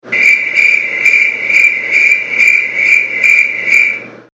Crickets